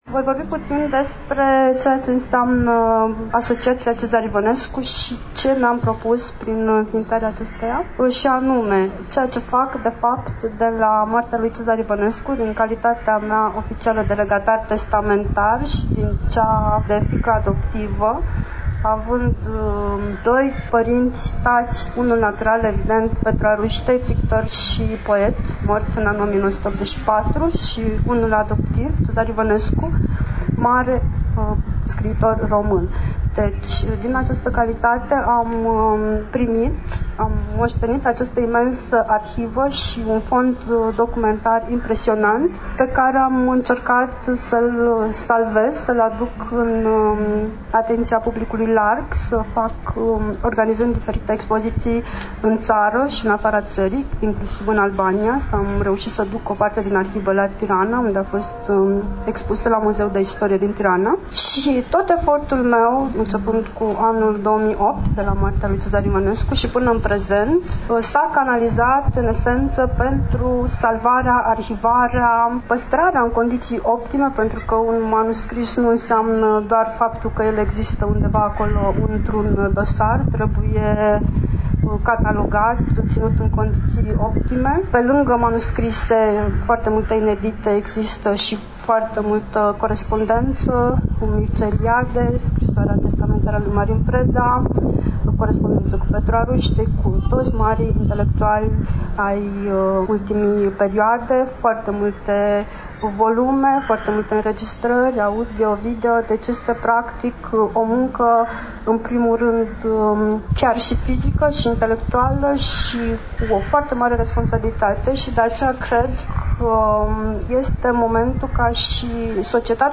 Reamintim că relatăm de la prima ediție a manifestării anuale – „Întâlnirile Cezar IVĂNESCU”, eveniment desfășurat, la Iași, în luna aprilie a acestui an, cu prilejul împlinirii a 15 ani de la plecarea „în satul stelelor” a poetului.